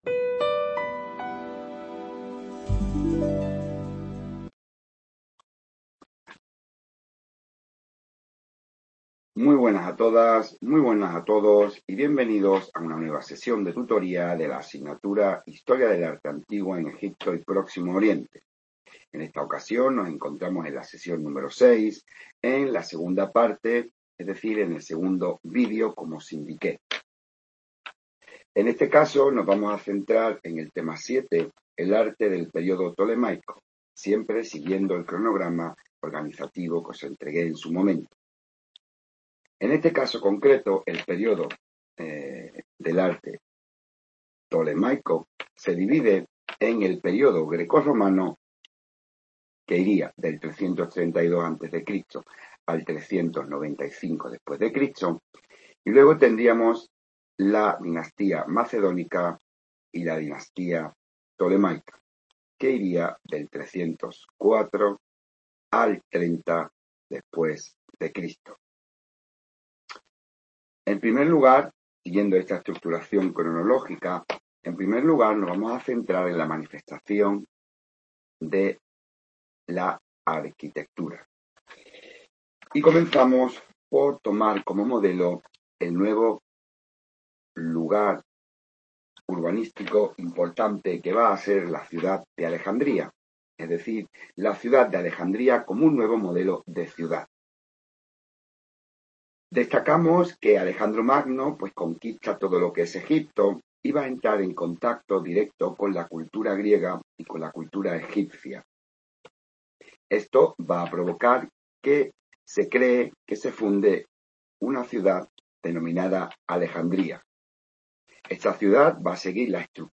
- TEMA 6 - ARTE DEL III PERIODO INTERMEDIO Y DEL PERIODO TARDIO - TEMA 7 - ARTE DEL PERIODO PTOLEMAICO - ¿Cómo redactar un tema para el examen? Video-clase grabada.